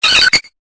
Cri de Togetic dans Pokémon Épée et Bouclier.